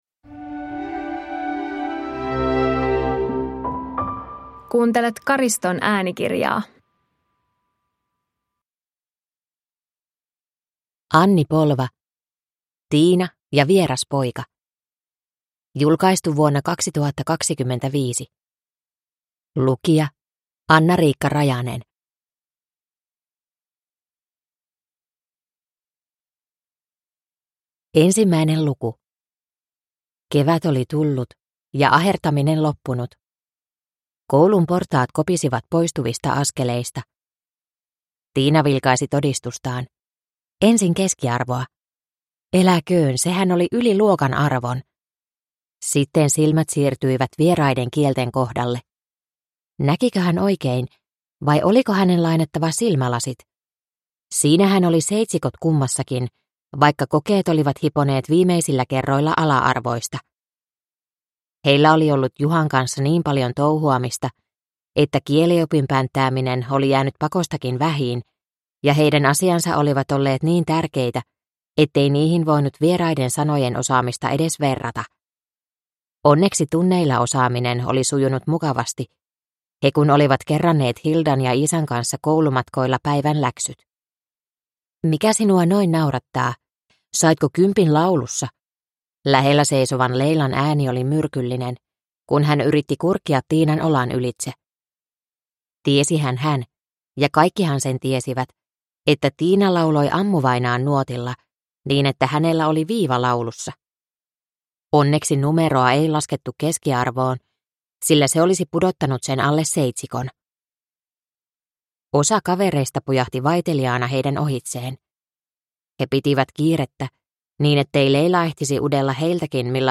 Tiina ja vieras poika (ljudbok) av Anni Polva